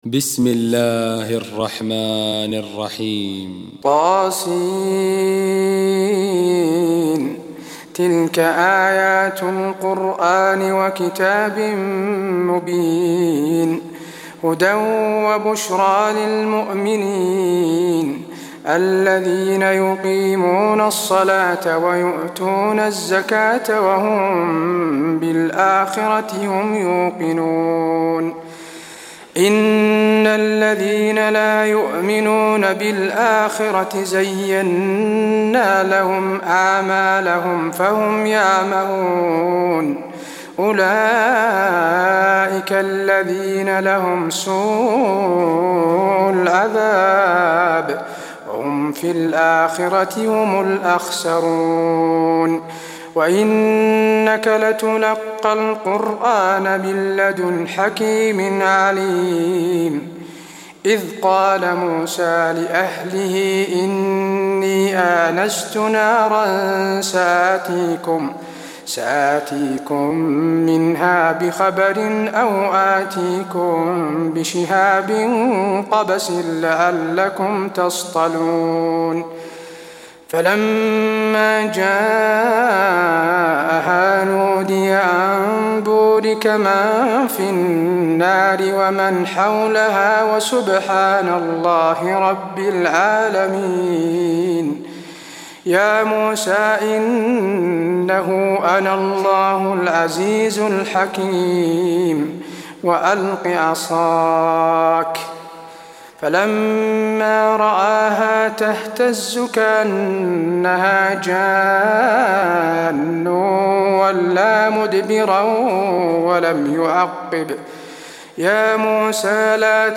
تراويح الليلة الثامنة عشر رمضان 1423هـ من سورة النمل (1-53) Taraweeh 18 st night Ramadan 1423H from Surah An-Naml > تراويح الحرم النبوي عام 1423 🕌 > التراويح - تلاوات الحرمين